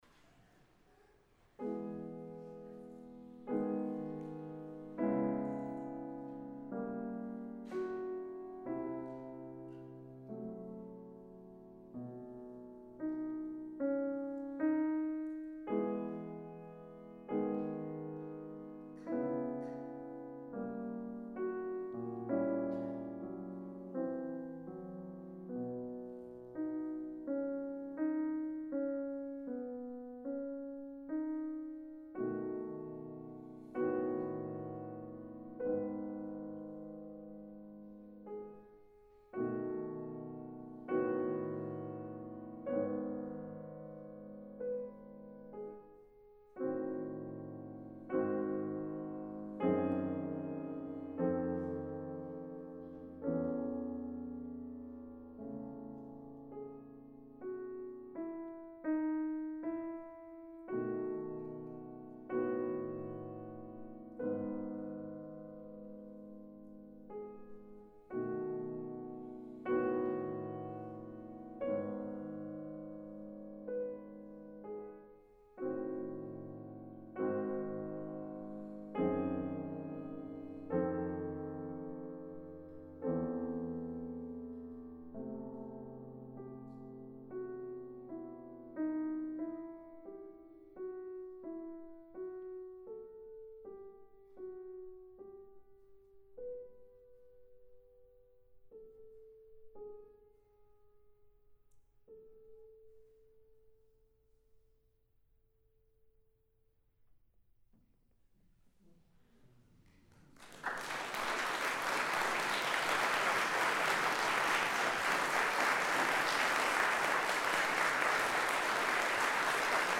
piano · Music Archive